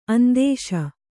♪ andēśa